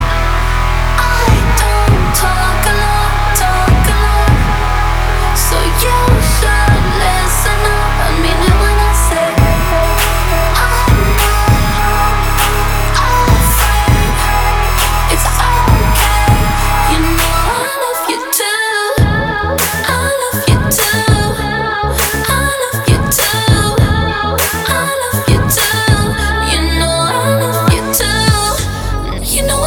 • Качество: 320, Stereo
поп
RnB
vocal